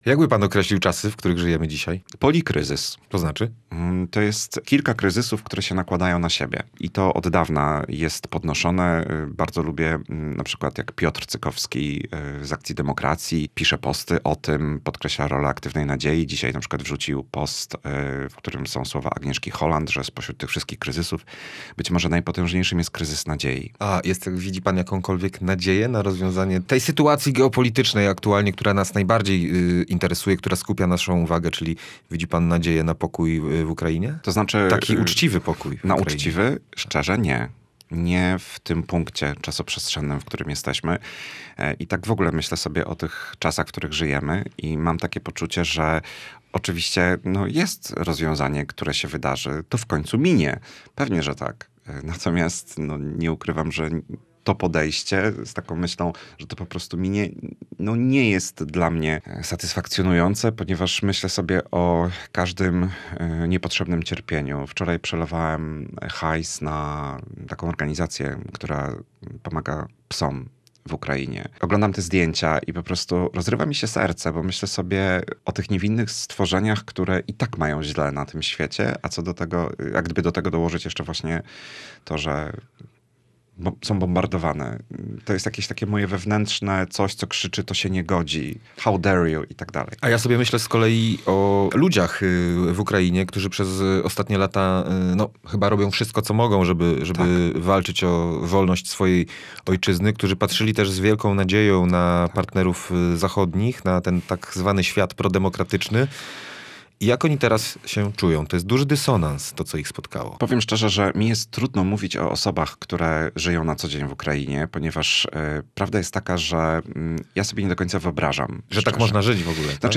Emocje w obliczu politycznych kryzysów. Rozmowa z psychologiem